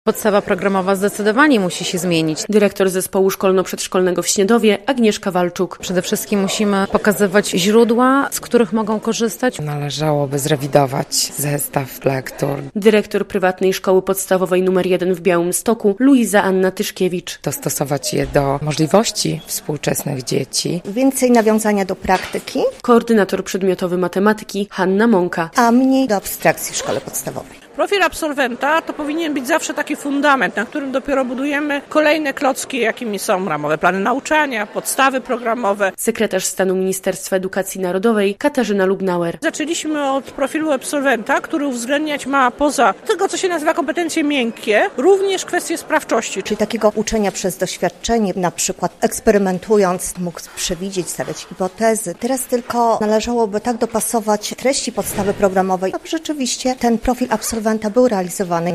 Debata o profilu absolwenta w Białymstoku, 11.04.2025, fot.
relacja
W debacie o profilu absolwenta w Białymstoku wzięli udział: wiceminister edukacji, podlaska kurator oświaty, wojewoda podlaski i nauczyciele z regionu.